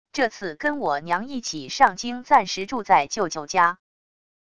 这次跟我娘一起上京暂时住在舅舅家wav音频生成系统WAV Audio Player